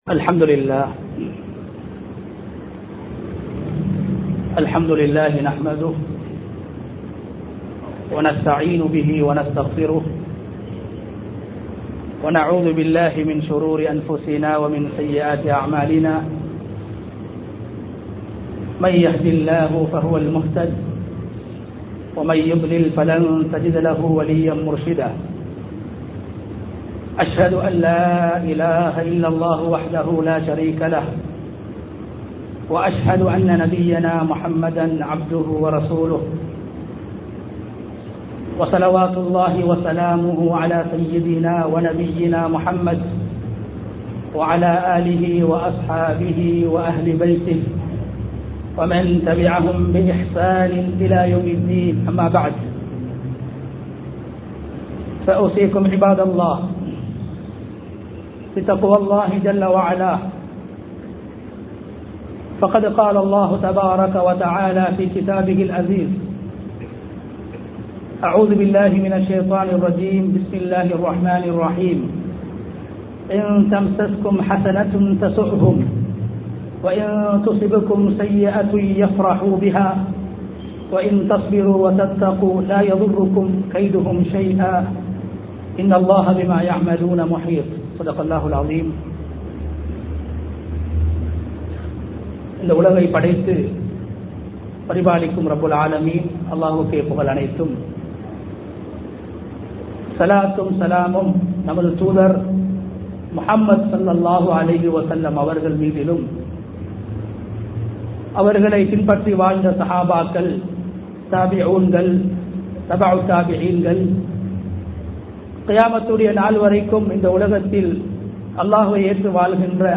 பிறரின் துன்பத்தில் இன்பம் காணாதீர்கள் | Audio Bayans | All Ceylon Muslim Youth Community | Addalaichenai
Colombo 15, Mattakkuliya, Kandauda Jumua Masjidh 2022-12-02 Tamil Download